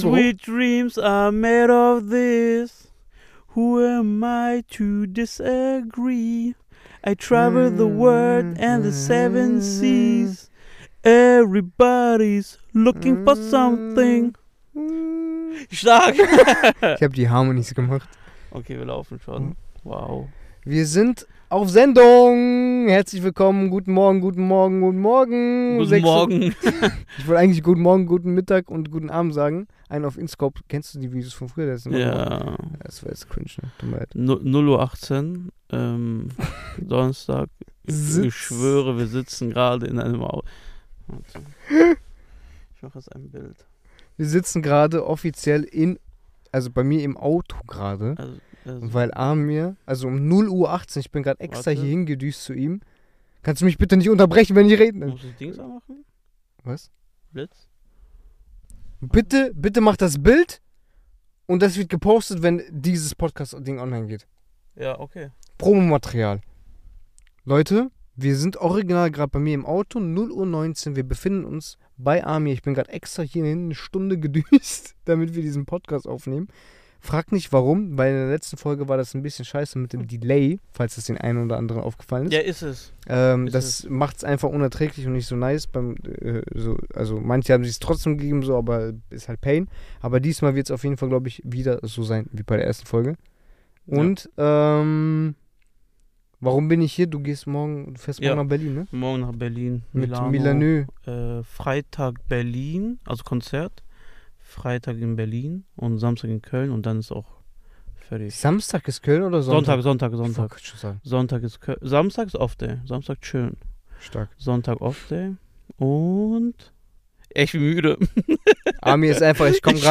Heute bei unserer Latenightsession gab's eine ausgiebige Diskussion über das Universum. Über die Theorie der Unendlichkeit.